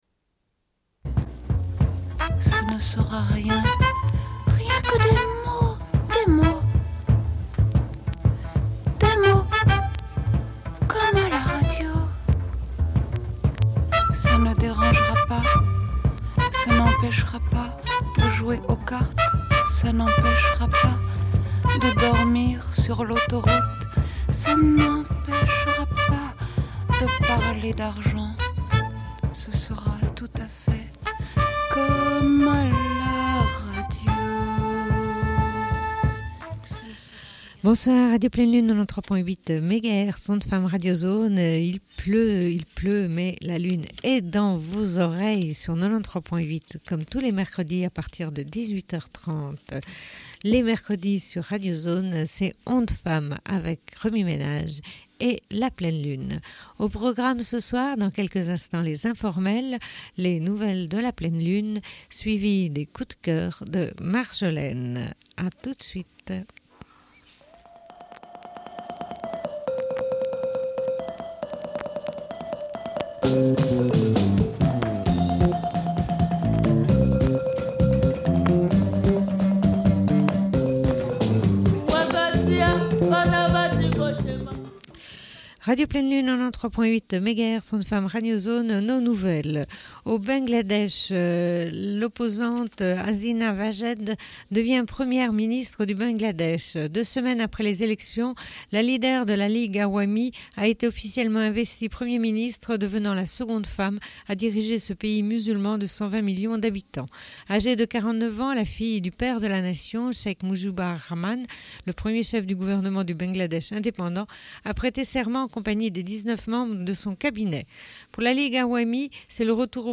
Bulletin d'information de Radio Pleine Lune du 04.10.1995 - Archives contestataires
Une cassette audio, face B